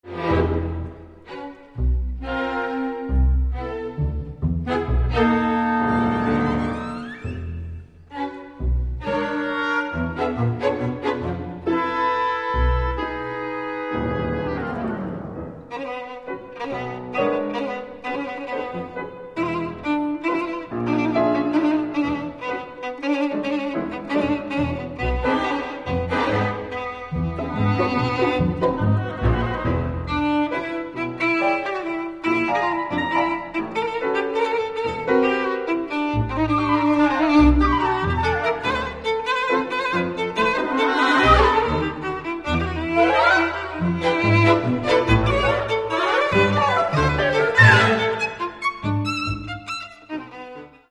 Каталог -> Класична -> Камерна